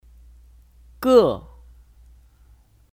个 (Gè 个)